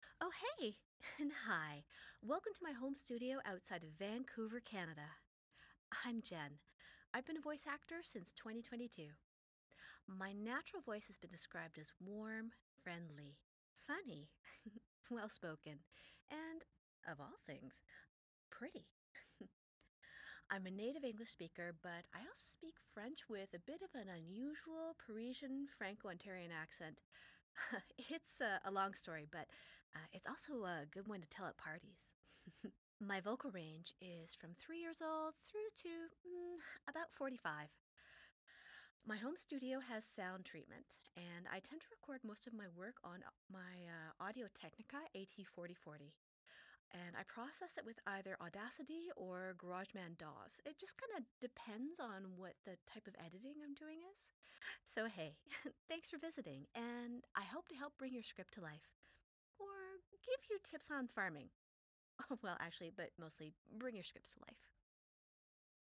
Female
Studio Quality Sample
Welcome To My Home Studio!